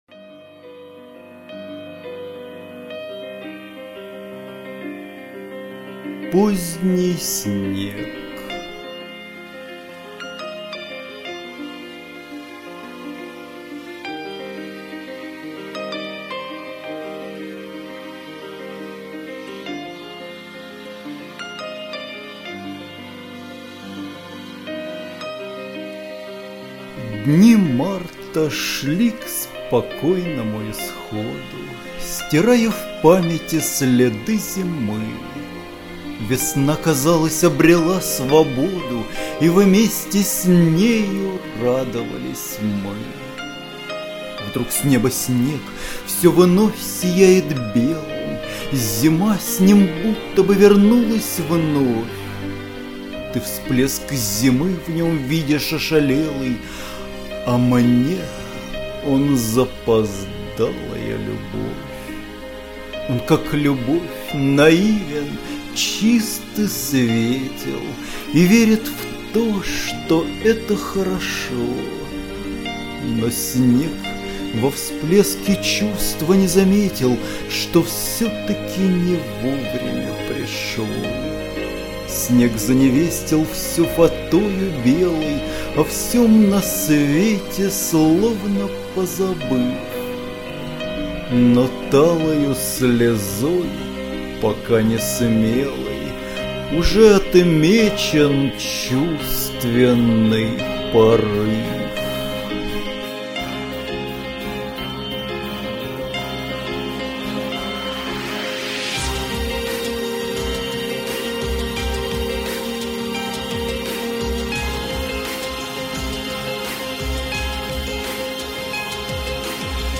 (в музыкальном оформлении: Д. Маликов. "Дыши")